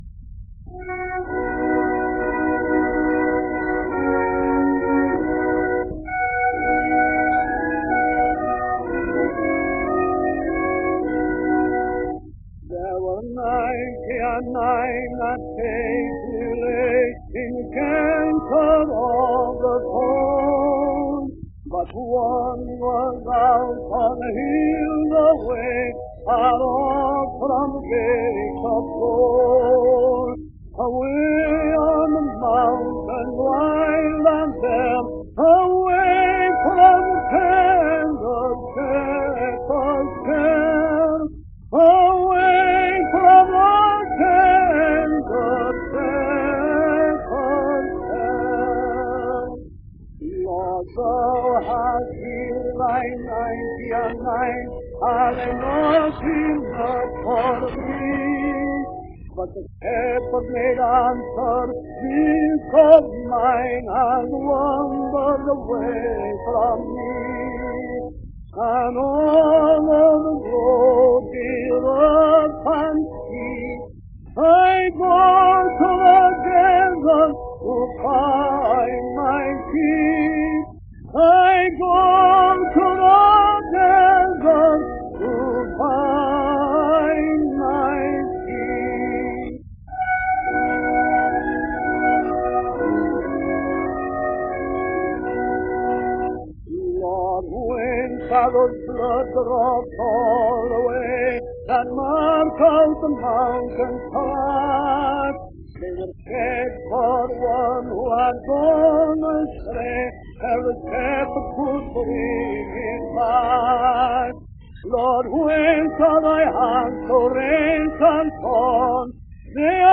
A pair of 1914 recordings by the famous tenor singer.